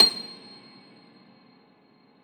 53e-pno26-C6.aif